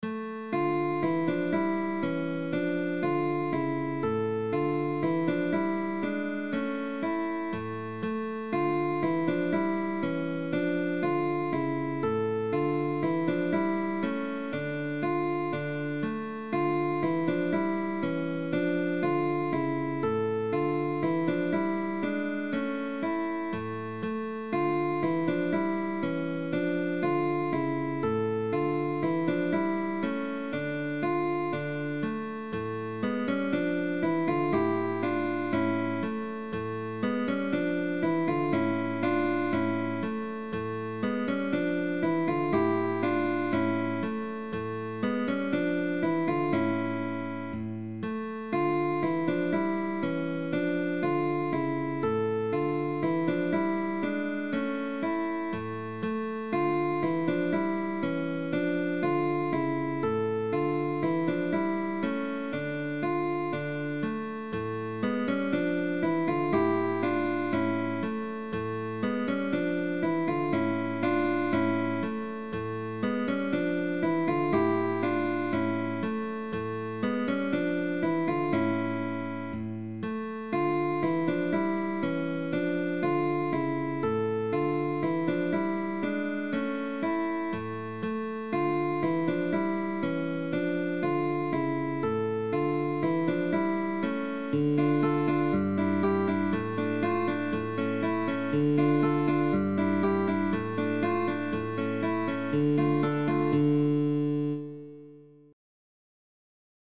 DÚO DE GUITARRAS
Indice y medio «Apoyando» y «Tirando». Arpegios de 3 dedos (p, i ,m).Pulgar,melodía en los graves.Cambios de posición.